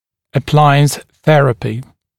[ə’plaɪəns ‘θerəpɪ][э’плайэнс ‘сэрэпи]аппаратная терапия